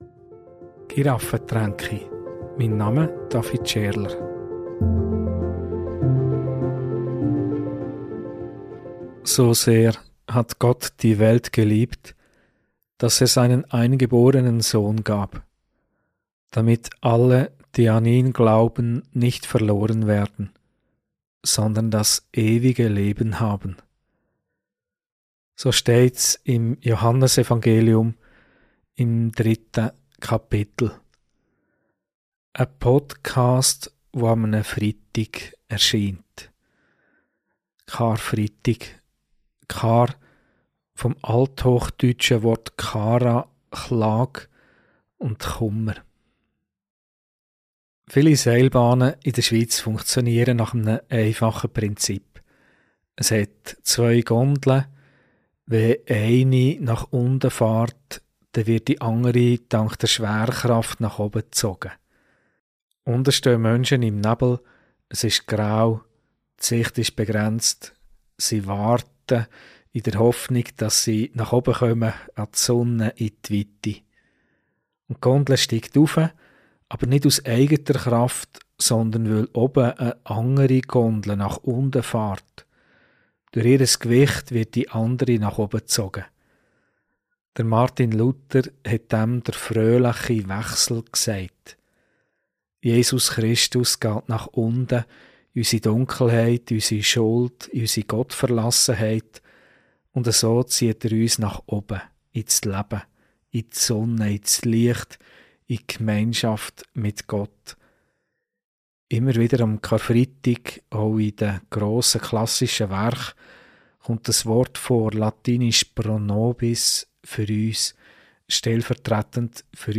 Predigt - Karfreitag: Unsere Antwort ~ Giraffentränke Podcast